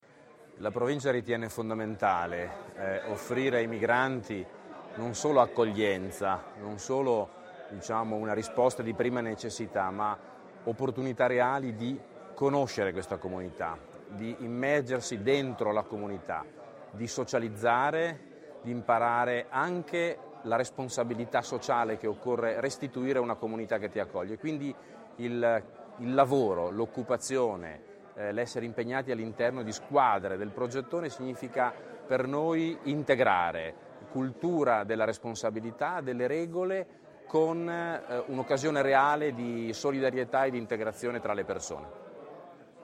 L'iniziativa è stata presentata questa mattina a Marco di Rovereto, negli spazi della Provincia presso "l'ex polveriera", in una conferenza stampa